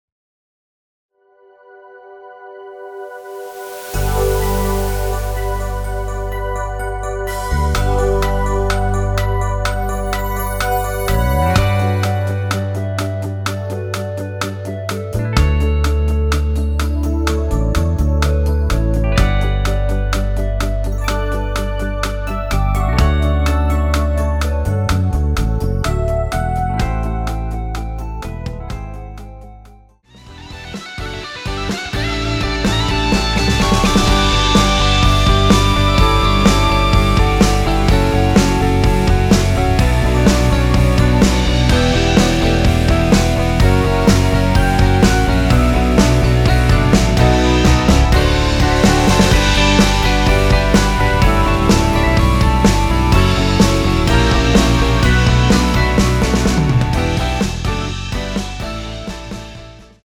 원키에서(+5)올린 멜로디 포함된 MR입니다.(미리듣기 확인)
앞부분30초, 뒷부분30초씩 편집해서 올려 드리고 있습니다.
중간에 음이 끈어지고 다시 나오는 이유는